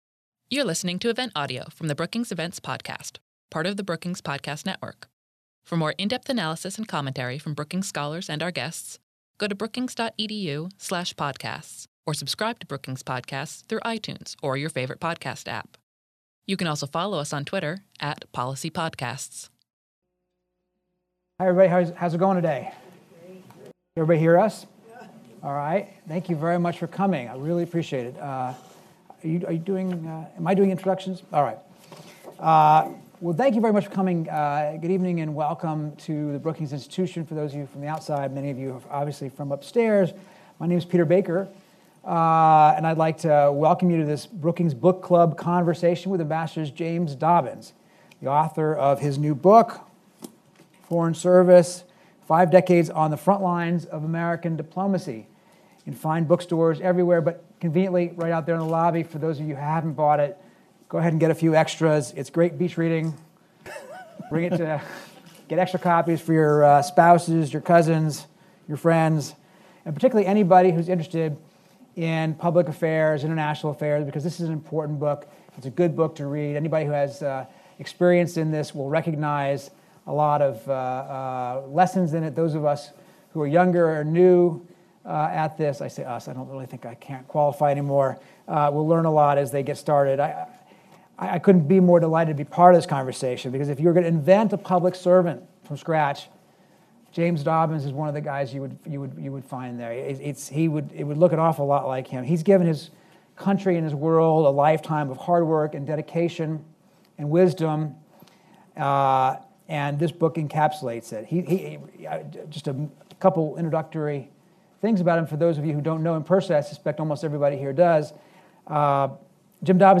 At this Brookings Book Club event, author James Dobbins discussed his new memoir “Foreign Service” with New York Times White House correspondent Peter Baker.